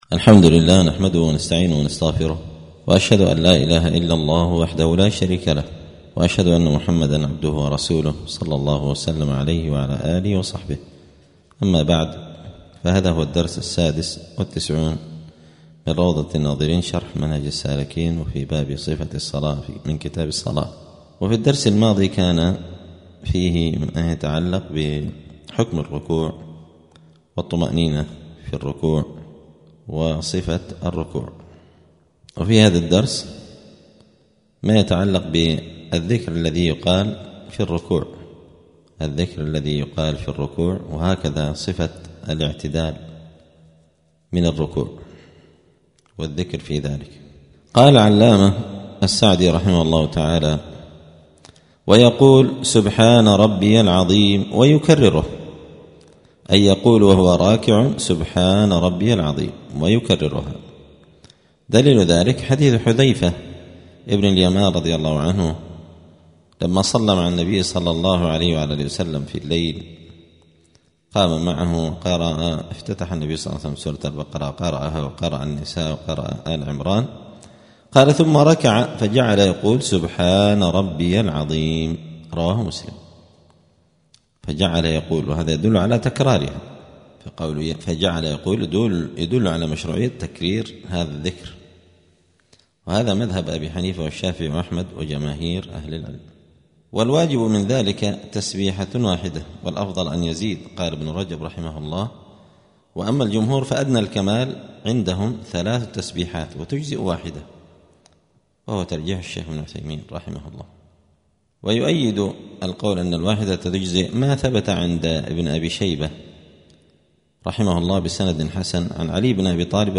*الدرس السادس والتسعون (96) {كتاب الصلاة باب صفة الصلاة صفة الاعتدال من الركوع والذكر في ذلك}*